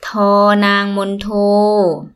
– toor – naang – monn – too
toor-naang-monn-too.mp3